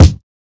DIRTY MPC KICK.wav